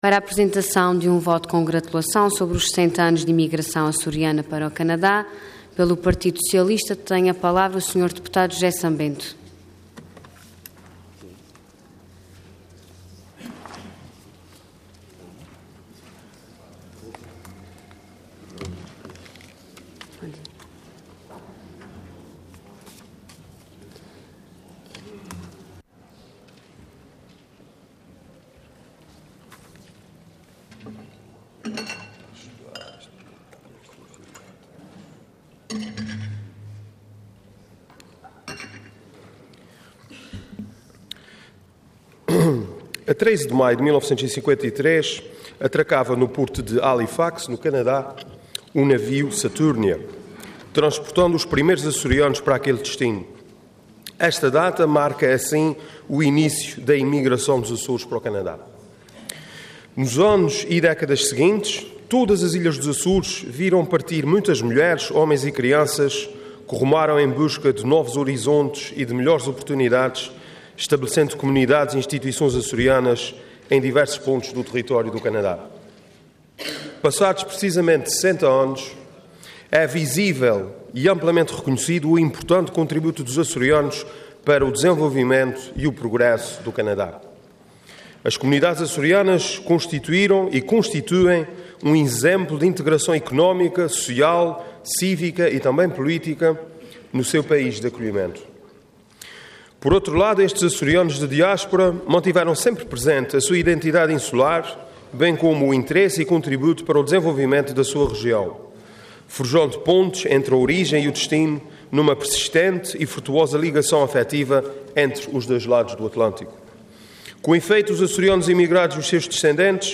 Intervenção Voto de Congratulação Orador José San-Bento Cargo Deputado Entidade PS